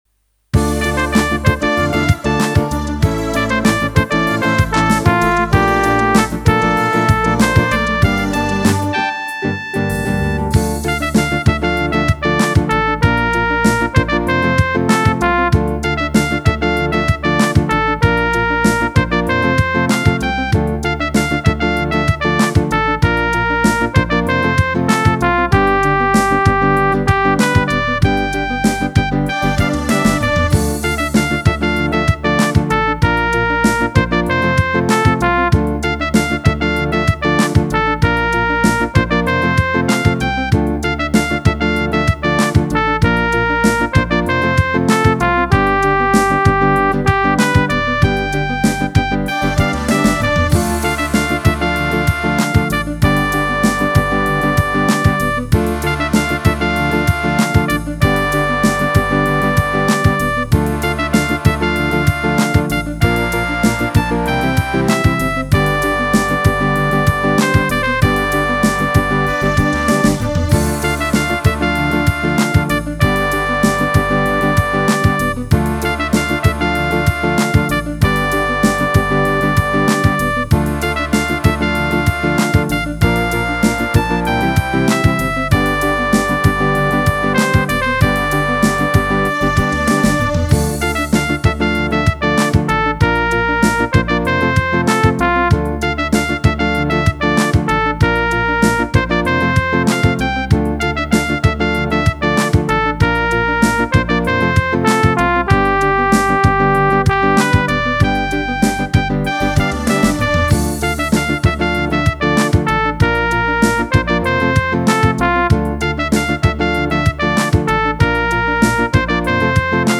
Ik voeg steeds nieuwe ritmedemo's bovenaan toe, scroll dus naar beneden om alles te zien.
028 Philly Pop